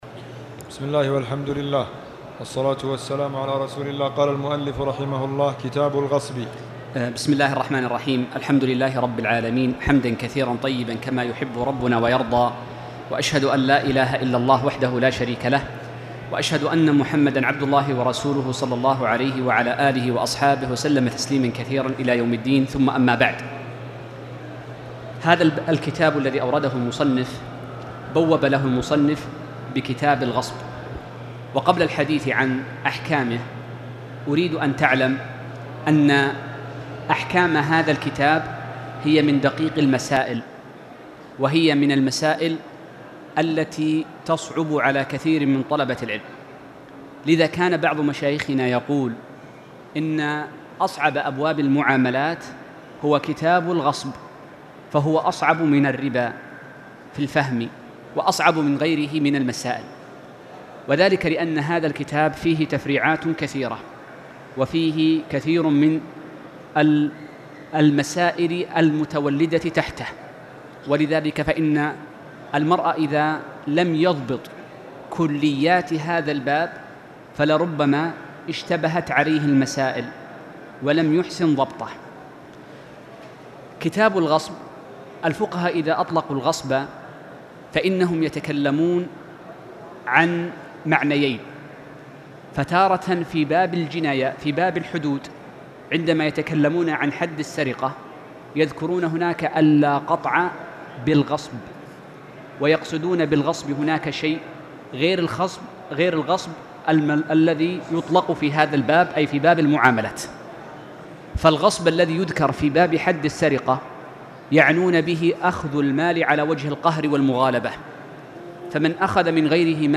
تاريخ النشر ٨ رجب ١٤٣٨ هـ المكان: المسجد الحرام الشيخ